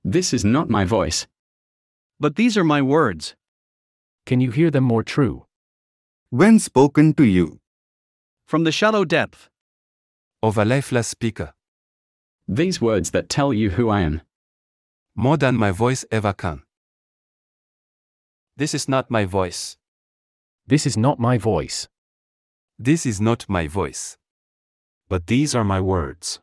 Text-to-speech
The poem
“This is not my voice” was rendered using Azure Text to Speech.